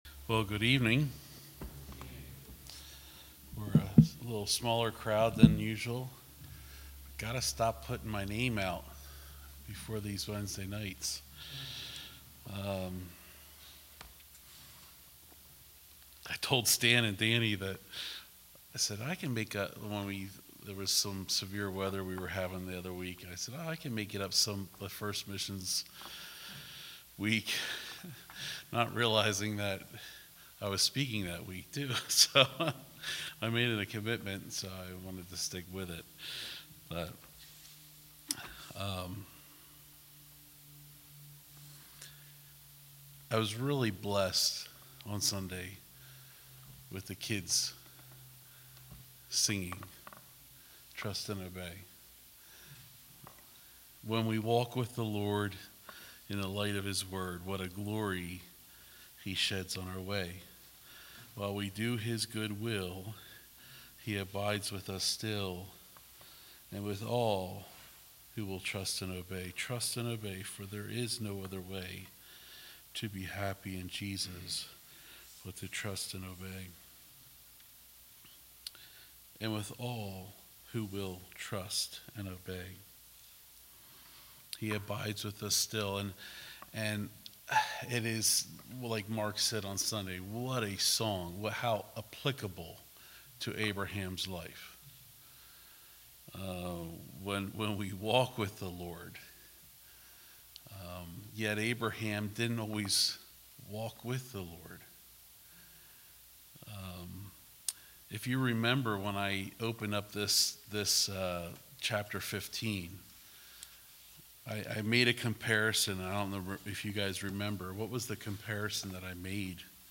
All Sermons Genesis 15:1-21